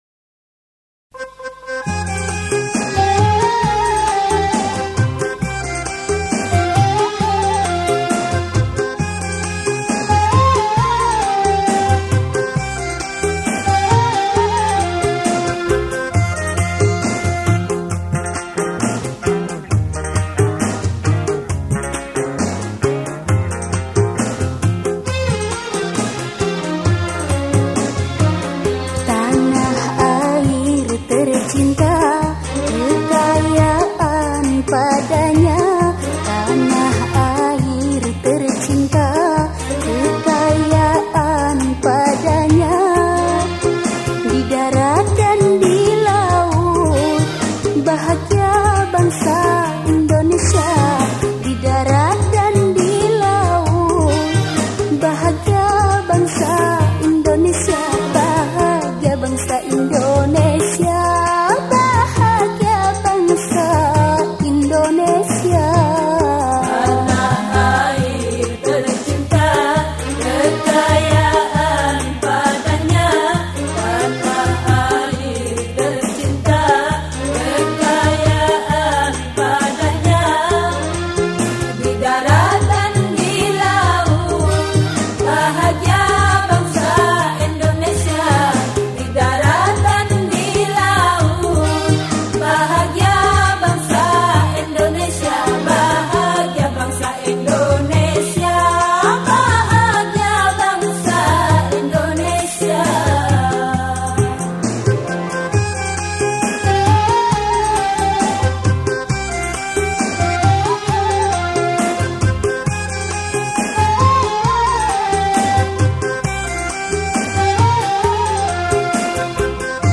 Genre Musik                                       : Dangdut
Instrumen                                            : Vokal